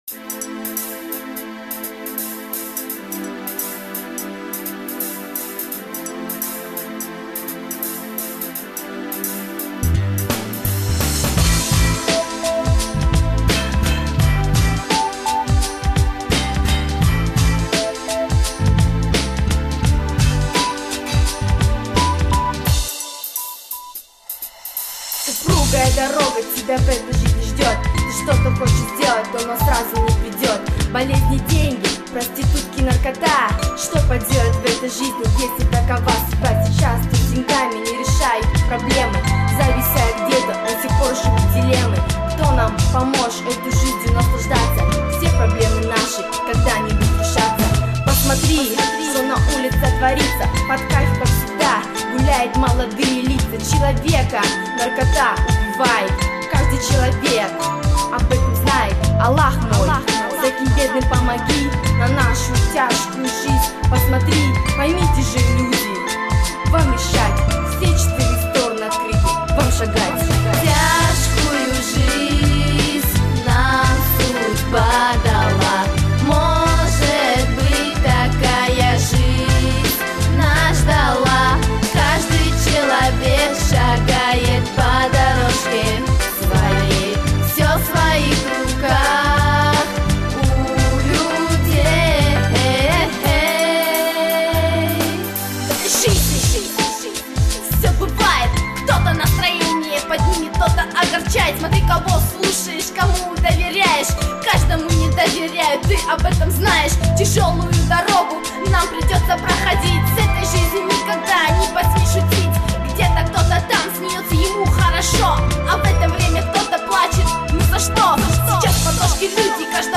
Русский РЭП [1]